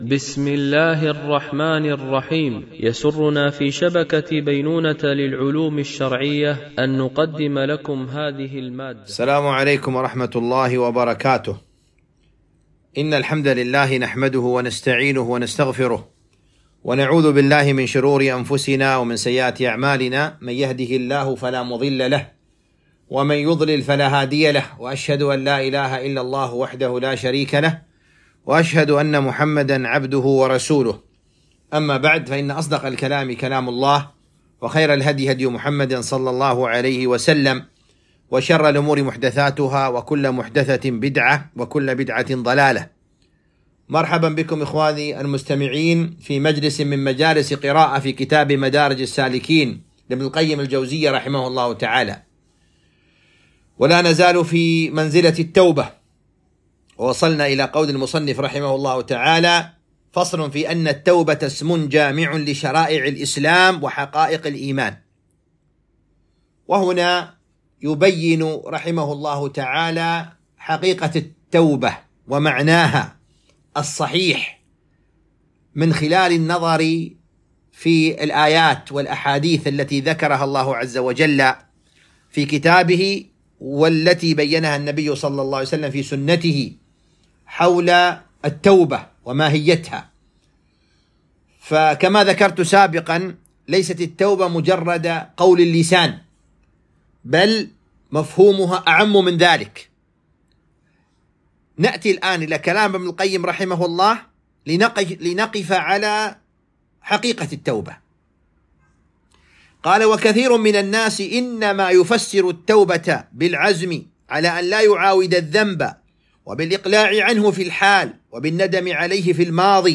قراءة من كتاب مدارج السالكين - الدرس 34